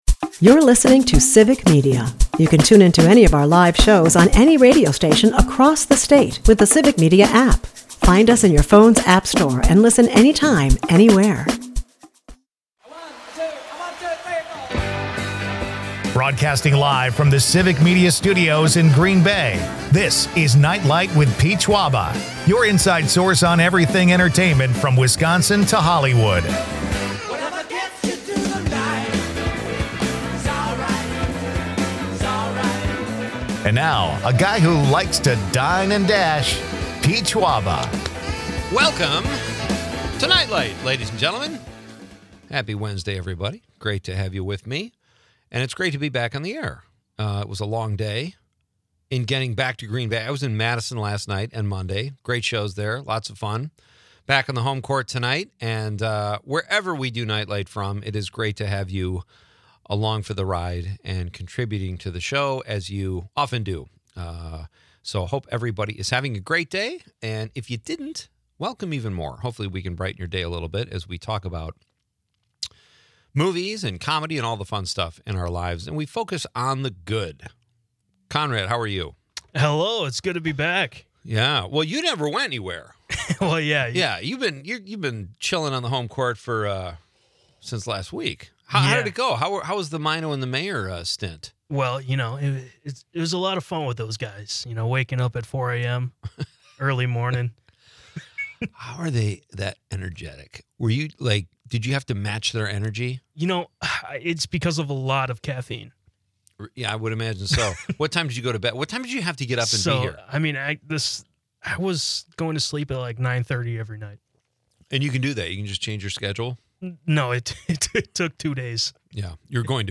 A lively discussion ensues, blending humor and nostalgia.